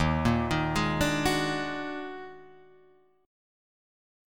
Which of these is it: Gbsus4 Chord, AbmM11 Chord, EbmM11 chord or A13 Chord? EbmM11 chord